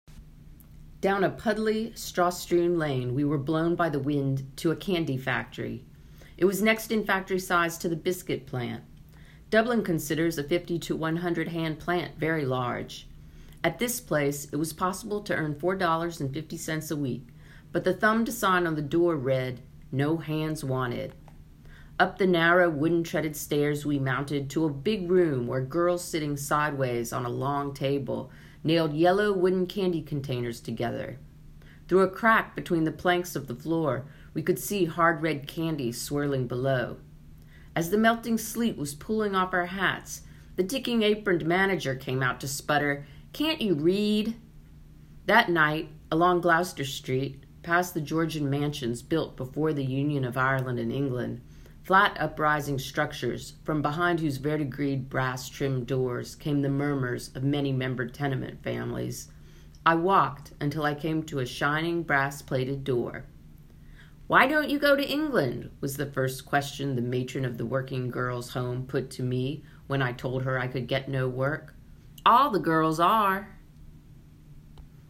In this reading from “Why Women Go to England”, Russell describes looking for work in Dublin with recently unemployed female munitions workers, like those she had labored with two years earlier in a Chicago armament plant.14: